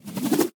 Minecraft Version Minecraft Version 1.21.5 Latest Release | Latest Snapshot 1.21.5 / assets / minecraft / sounds / mob / breeze / charge2.ogg Compare With Compare With Latest Release | Latest Snapshot
charge2.ogg